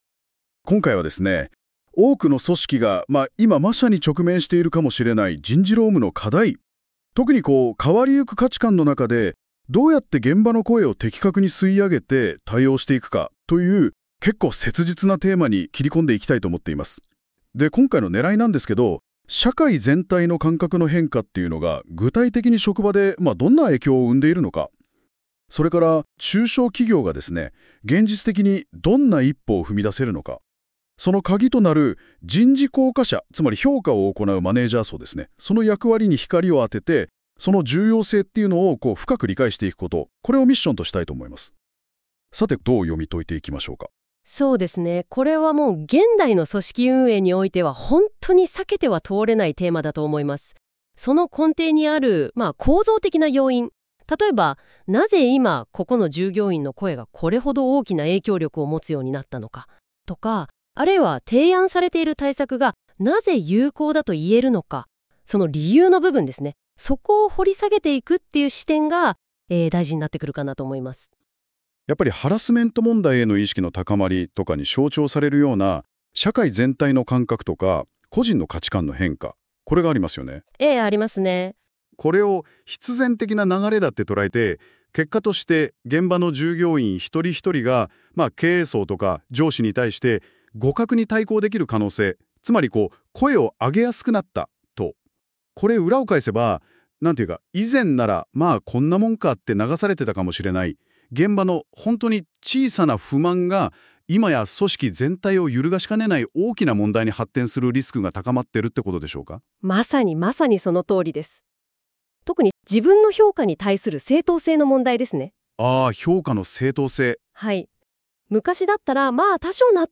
今回は、以下の本文を《生成AI：GoogleNotebookLM》で、２人の対話形式で解説した音声をも添付しています。
音声は可能な限り編集していますが、発音間違い等はシステム上一部残っています。